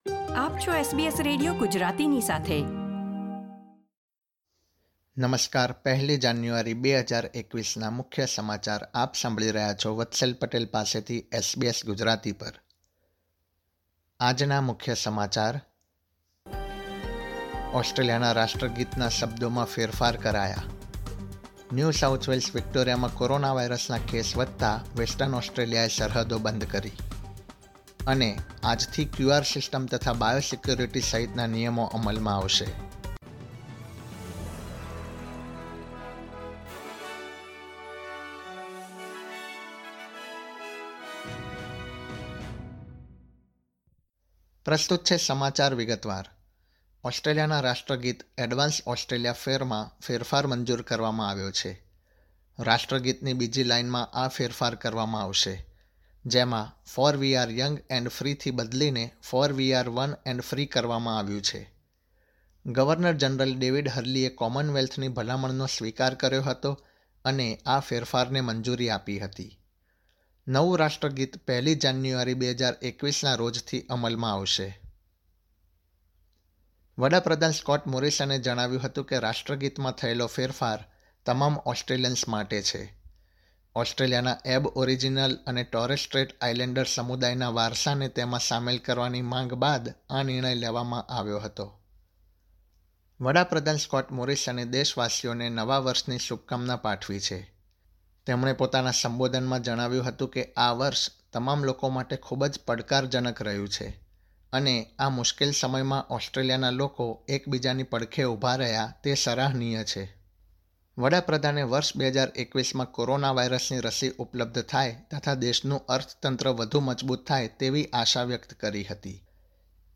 gujarati_0101_newsbulletin.mp3